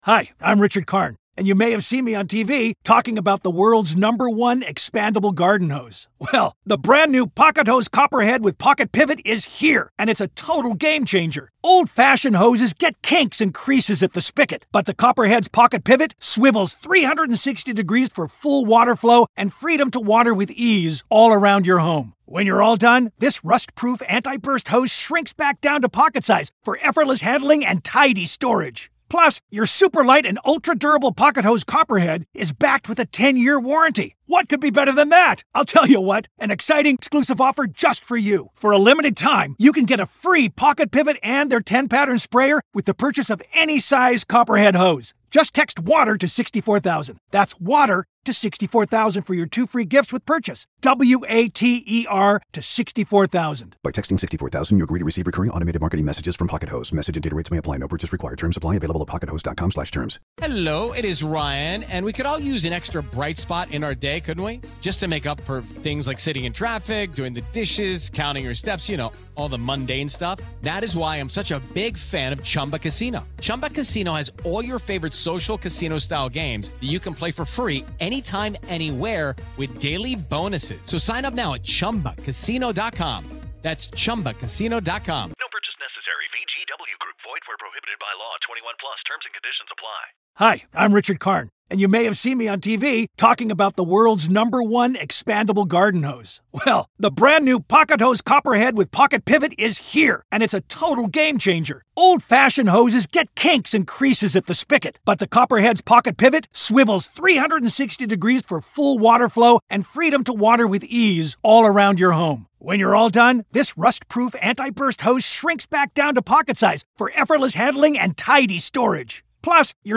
Cross-Examination